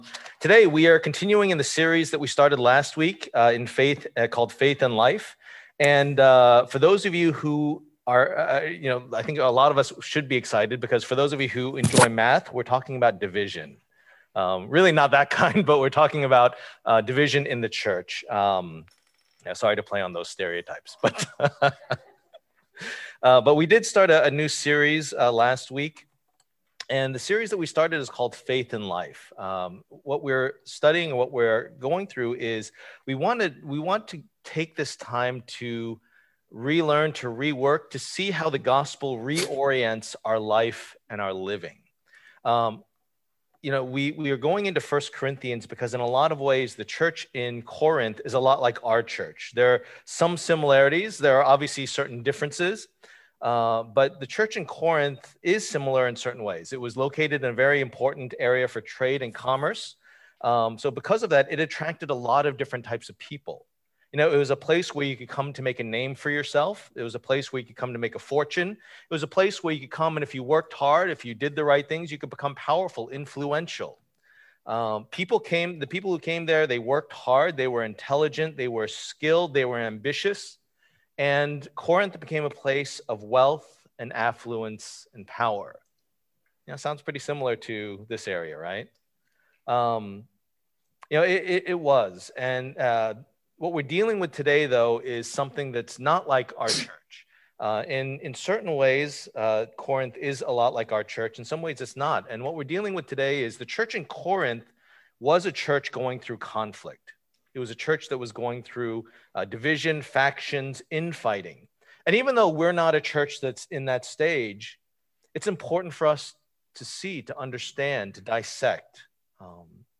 Passage: 1 Corinthians 3:1-9, 1 Corinthians 3:16-23 Service Type: Lord's Day